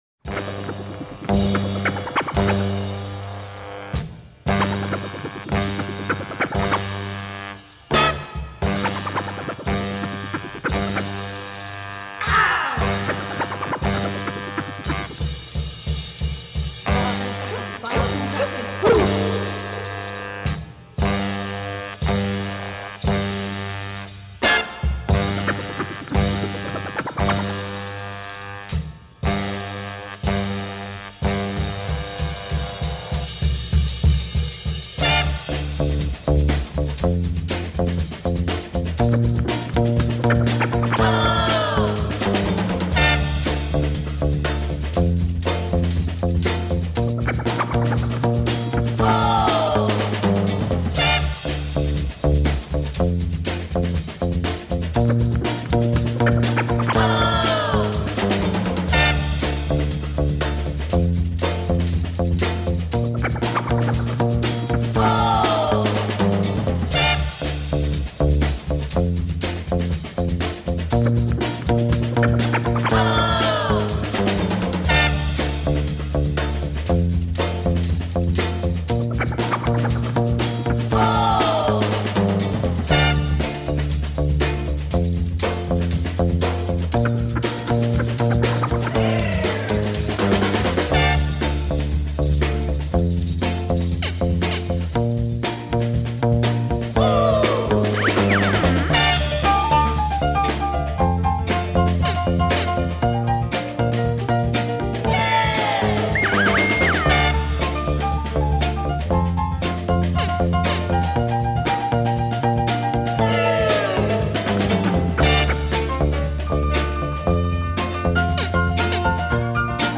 fabulous, devastating funky track
Dope bboy breaks !